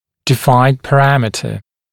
[dɪ’faɪnd pə’ræmɪtə][ди’файнд пэ’рэмитэ]заданный параметр (определенный в рамках к.-л. методики)